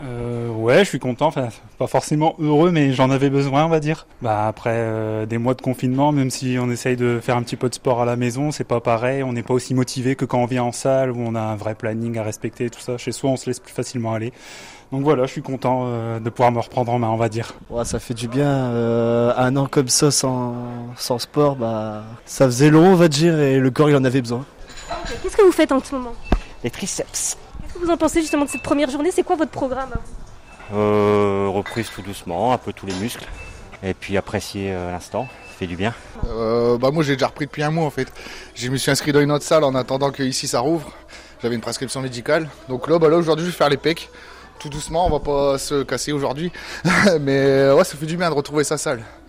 Enthousiasme des adhérents de salles de sport : reportage à Calais